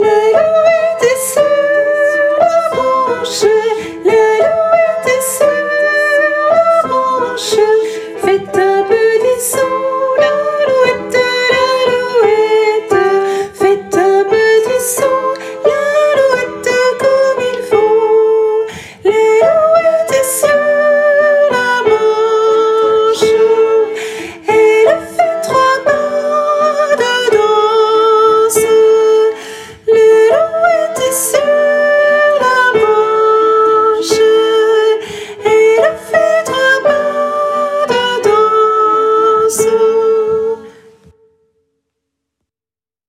- Œuvre pour choeur à 4 voix mixtes (SATB)
- chanson populaire de Lorraine
MP3 versions chantées
Soprano et autres voix en arrière-plan